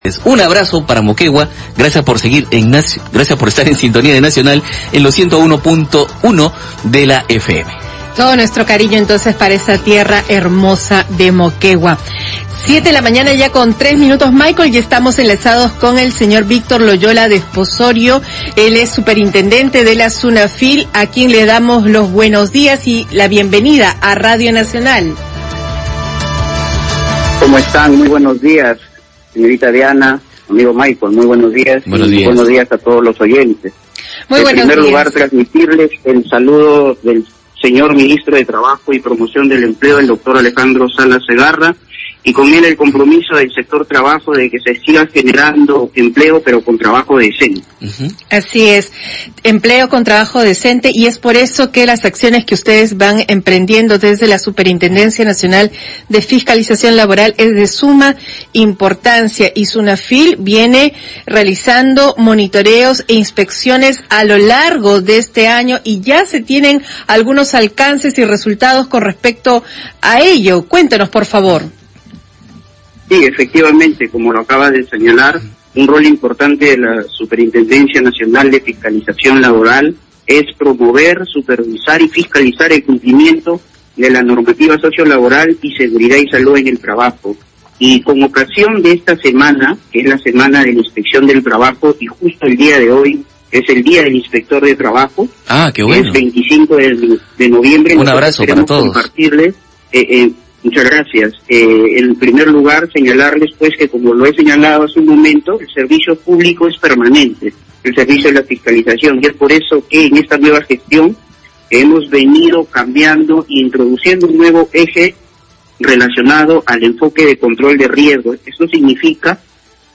Entrevista al superintendente de la Sunafil, Víctor Loyola